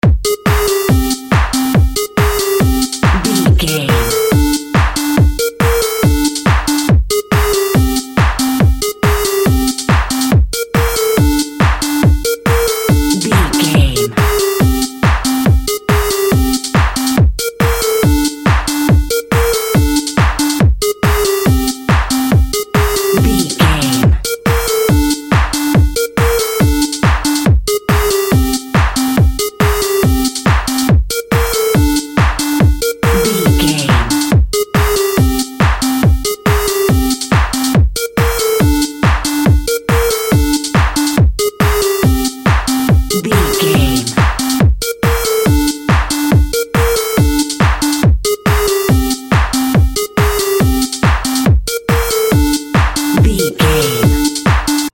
Aeolian/Minor
energetic
high tech
hypnotic
industrial
drum machine
synthesiser
synth lead
synth bass